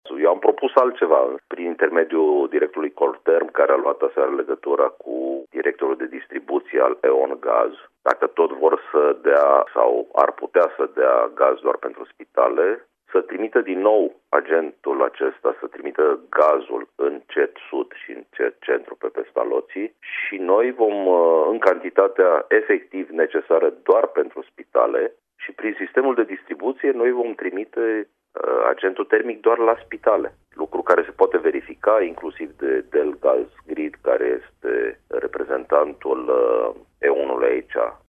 Precizarea a fost făcută la Radio Timișoara de subprefectul Ovidiu Drăgănescu.